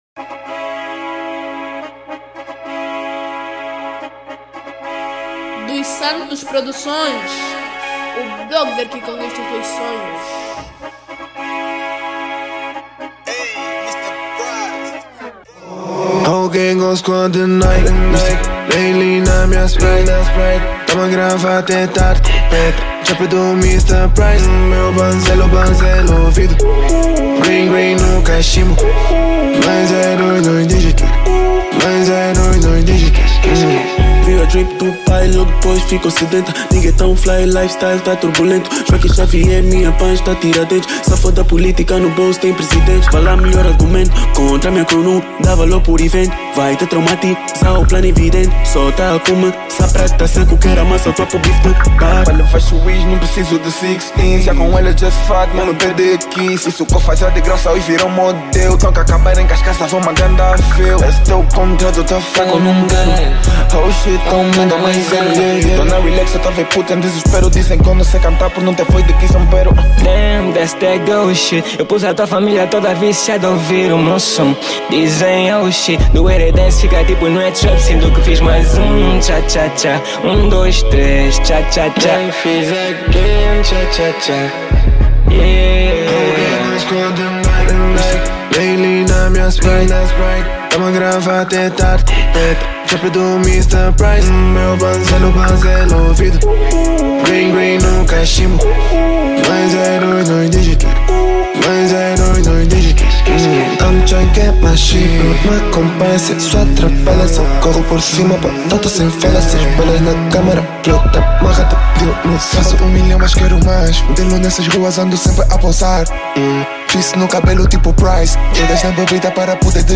Categoria  Trap